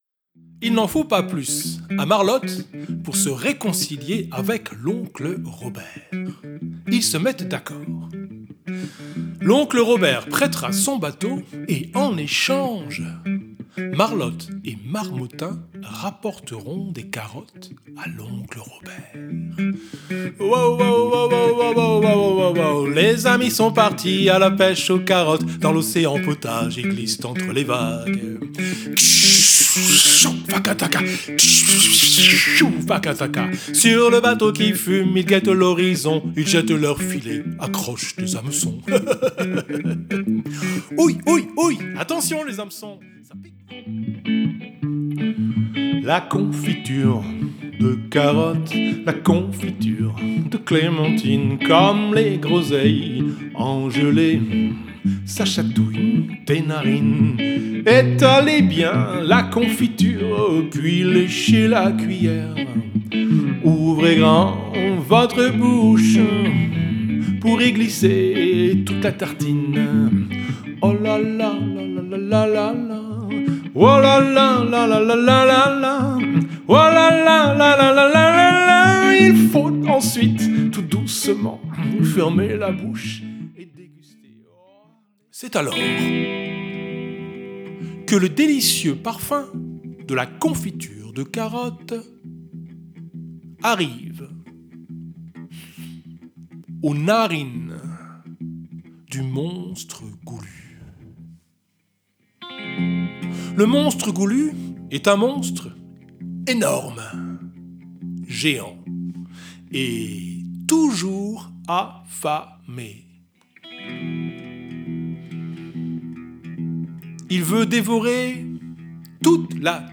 Une aventure racontée et chantée
guitare électrique, narration et chant